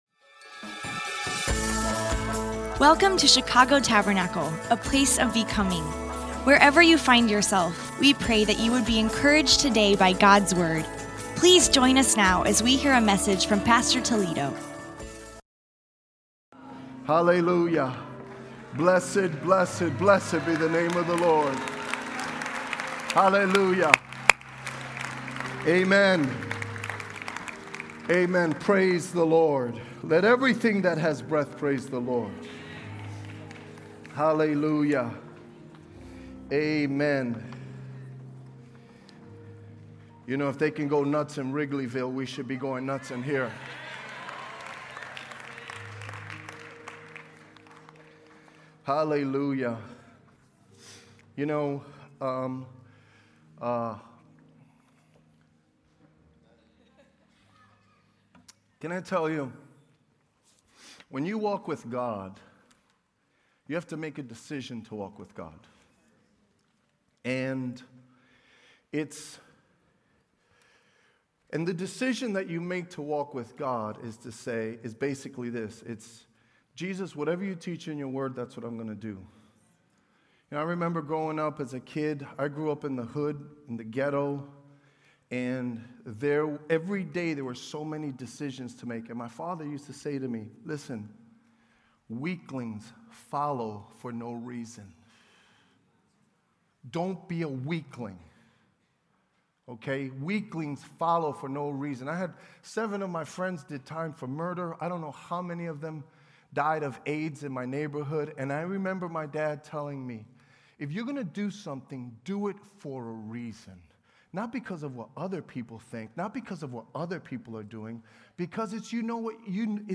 Finance-Final-Sermon.mp3